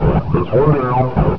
player_dead3.ogg